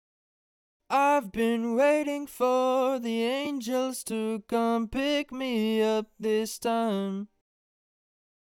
Raw Vocal Without Processing